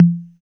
81 808 TOM.wav